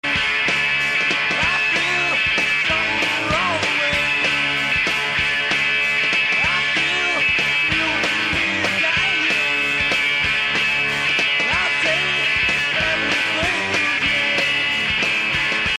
Community World Theater, Tacoma, WA, US